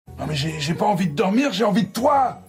Texture-Pack/assets/minecraft/sounds/mob/zombie/wood2.ogg at master